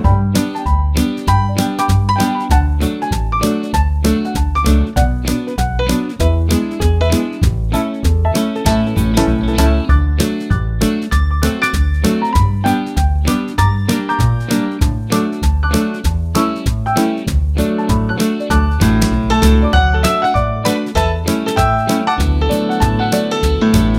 Minus Piano Easy Listening 2:05 Buy £1.50